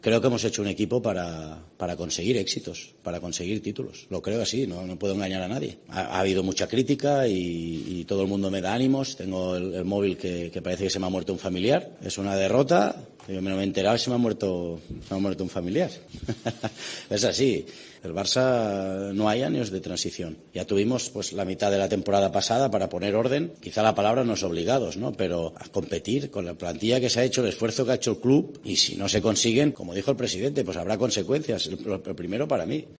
Barcelona | Rueda de Prensa